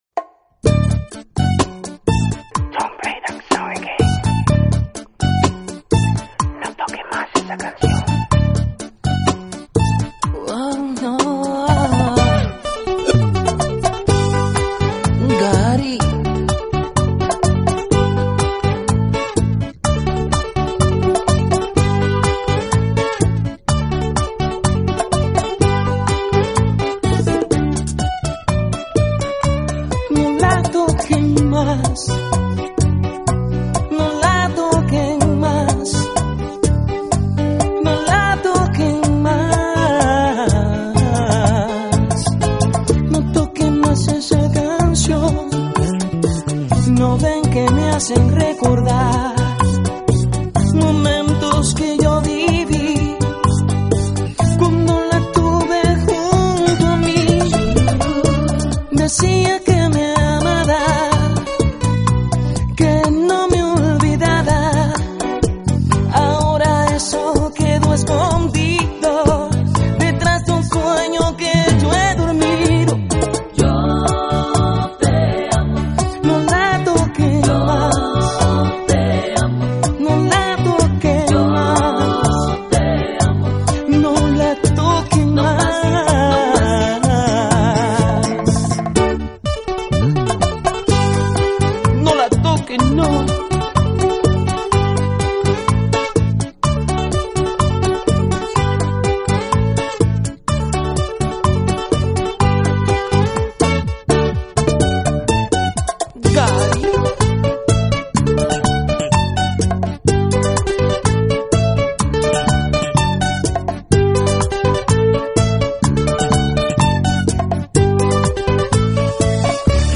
Categoría Latin/Spanish